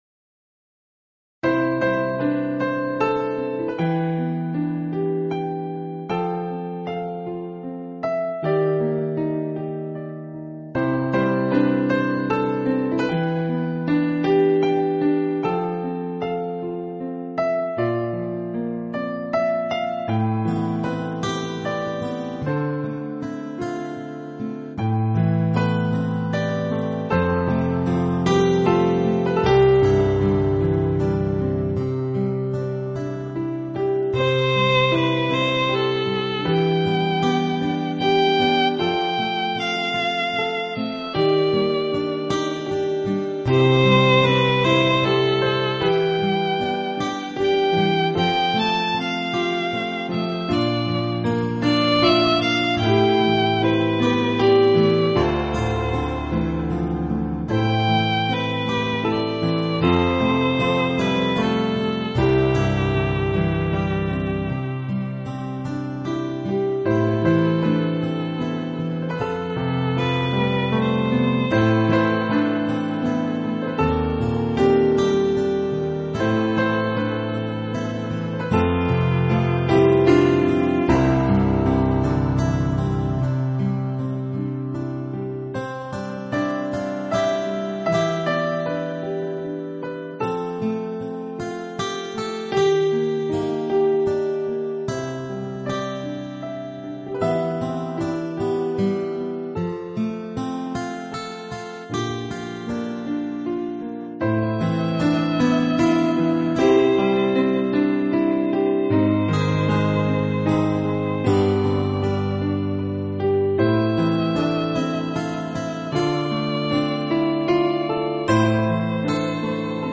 最纯净无暇的乐声及充满想像的琴韵